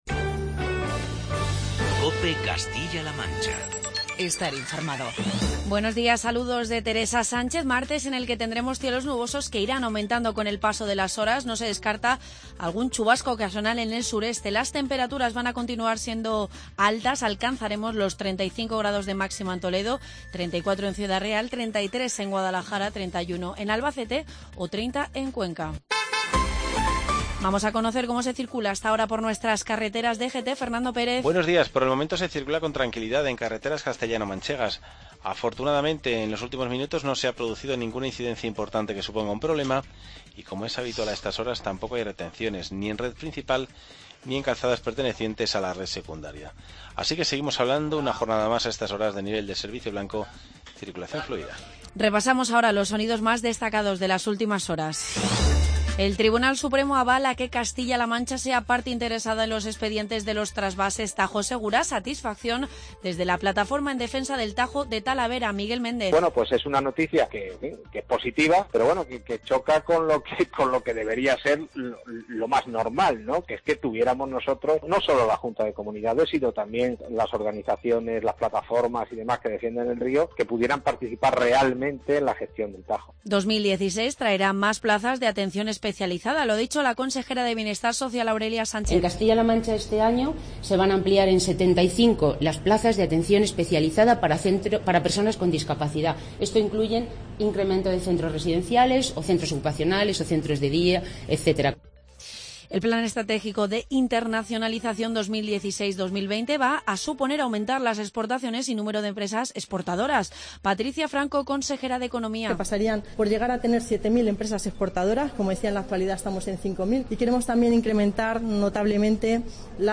Informativo regional